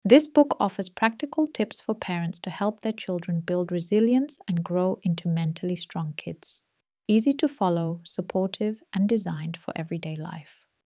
Hörbuchrobe englisch
english-sample-voice-clone-YrD4qbvgVGC56Gpn.mp3